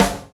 07_Snare_17_SP.wav